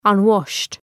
Shkrimi fonetik {ʌn’wɒʃt} ( mbiemër ) ✦ i palarë